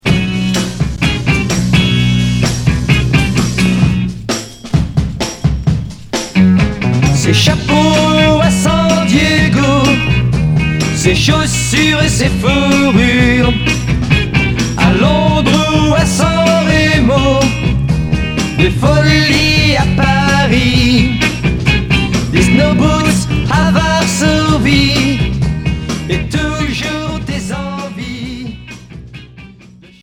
Beat rock